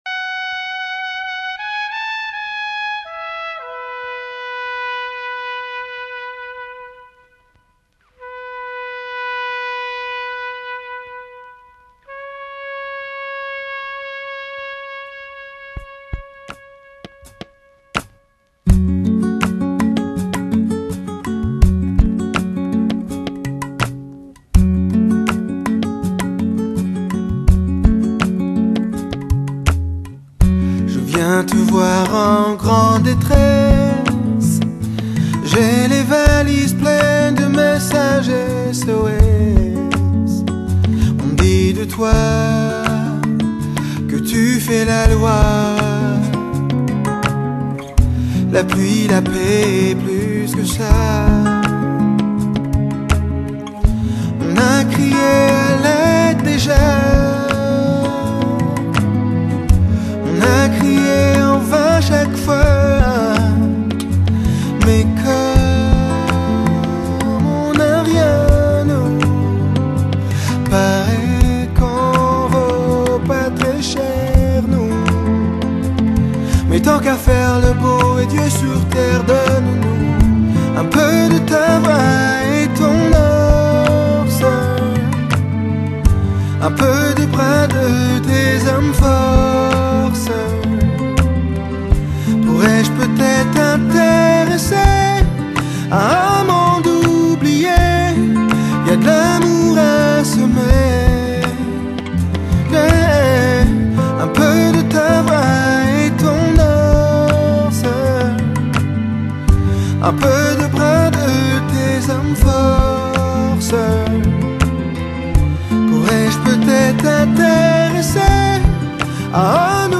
Activité principale Chanteur Auteur-compositeur-interprète
Genre musical R'n'B, Dance, variété
Instruments Chant, guitare